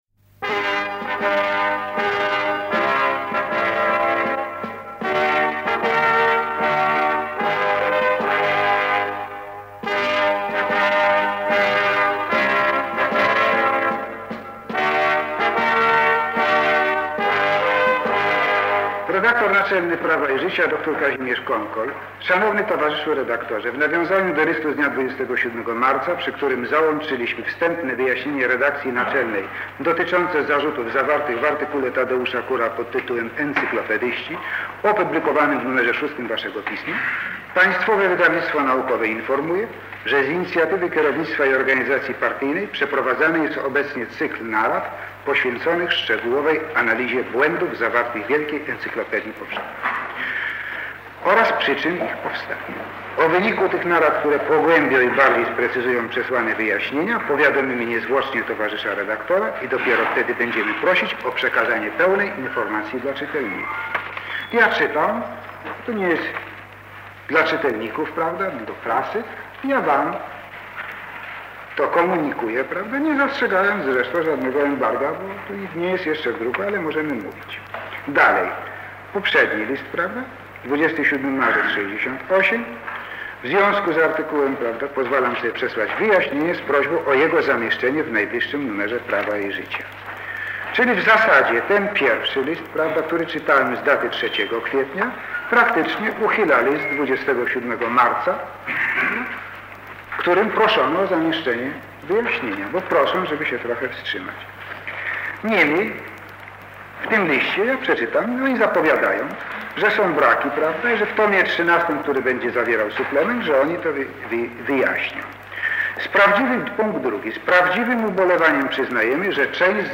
KUR I (cz.2) : relacja [dokument dźwiękowy] - Pomorska Biblioteka Cyfrowa